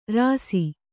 ♪ rāsi